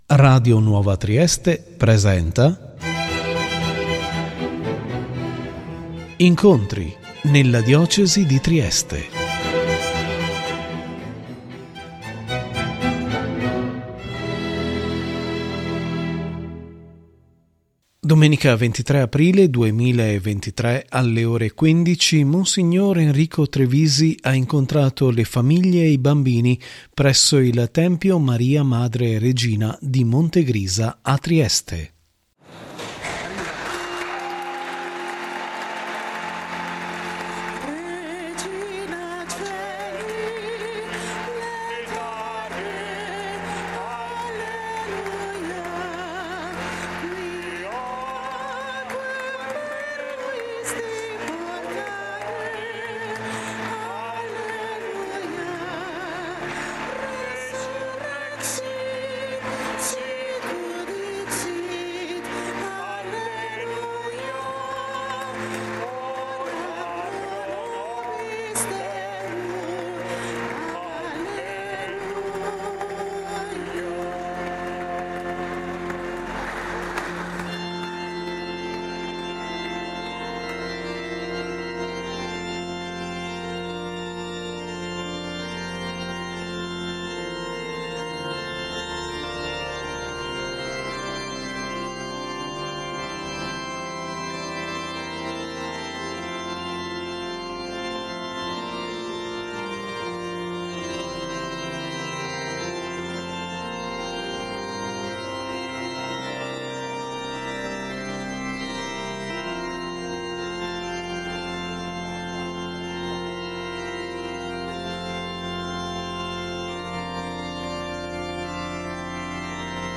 ♦ Domenica 23 aprile 2023 alle ore 15 Mons. Enrico Trevisi ha incontrato le famiglie ed i bambini presso il tempio Maria Madre e Regina di Monte Grisa a Trieste
Speciale Diocesi – 23 aprile 2023 – Momento di Preghiera e Incontro con i bambini e le Famiglie Mons. Enrico Trevisi